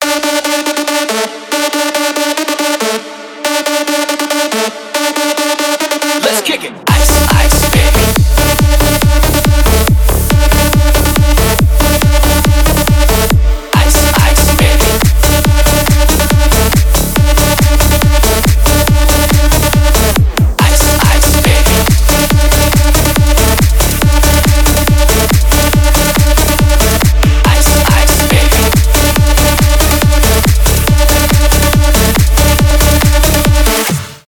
tech house
клубные
electro house
ремиксы